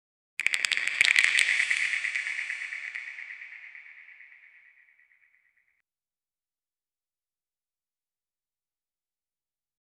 Royalty-free Whip sound effects
Whip crack from the movie roots remake
whip-crack-from-the-movie-xwdnamg4.wav